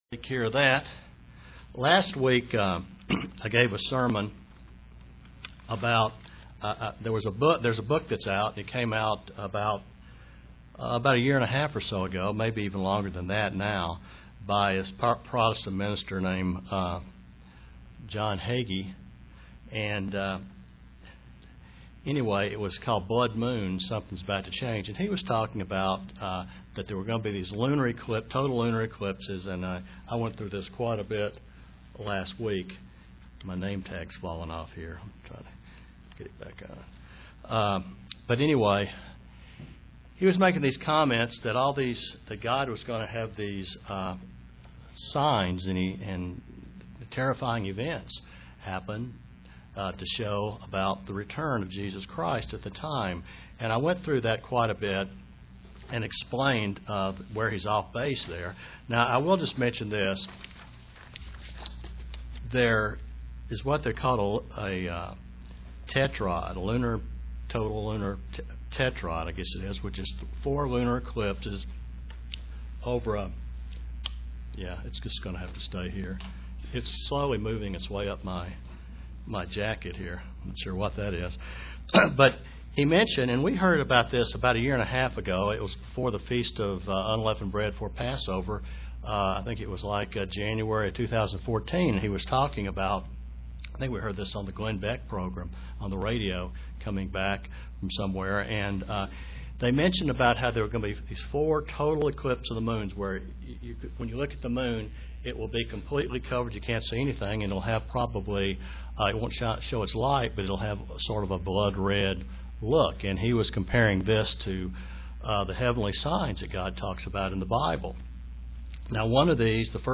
Given in Kingsport, TN
UCG Sermon Studying the bible?